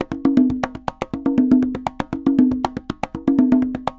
Congas_Candombe 120_1.wav